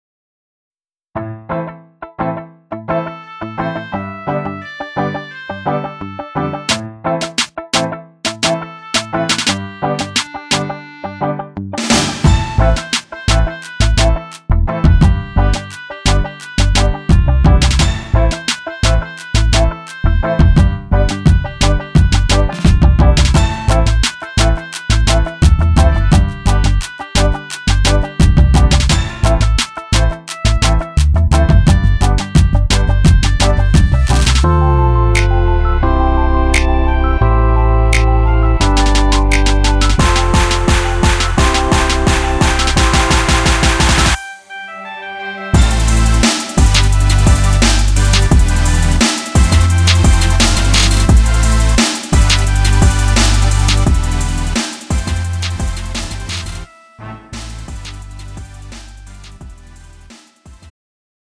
음정 -1키 3:14
장르 가요 구분 Pro MR
Pro MR은 공연, 축가, 전문 커버 등에 적합한 고음질 반주입니다.